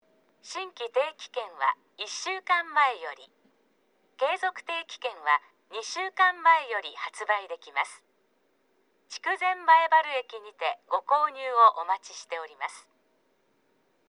啓発放送（定期券購入案内）